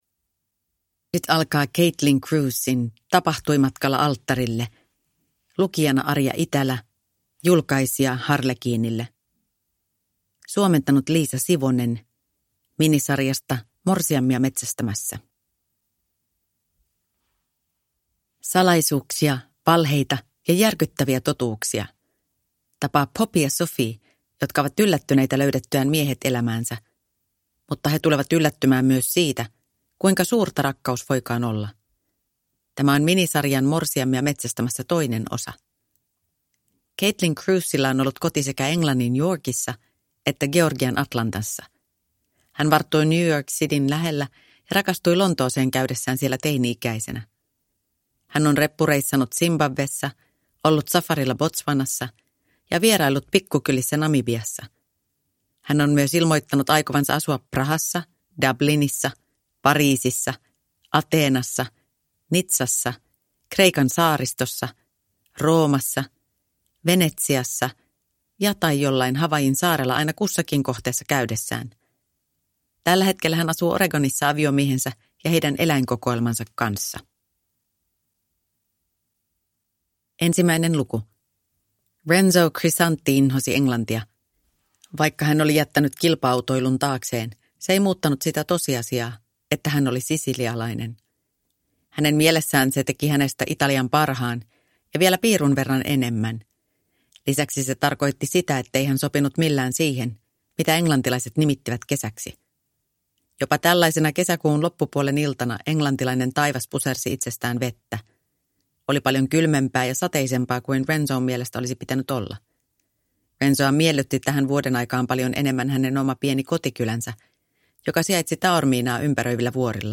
Morsiamia metsästämässä (ljudbok) av Caitlin Crews